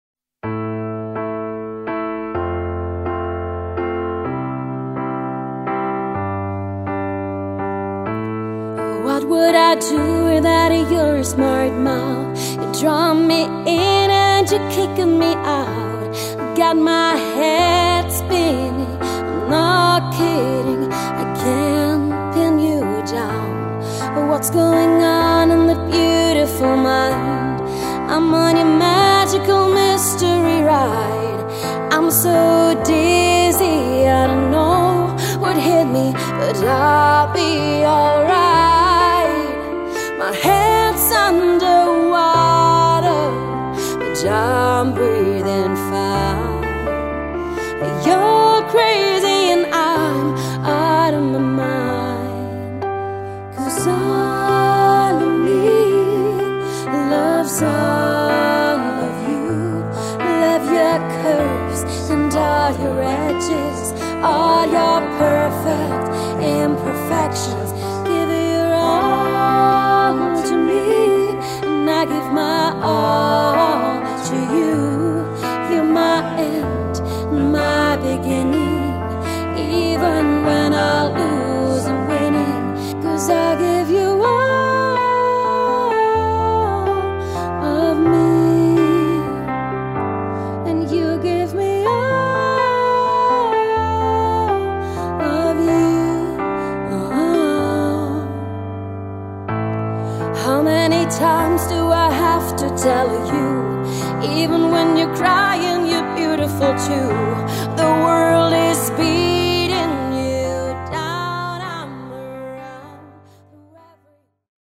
PIANO & GESANG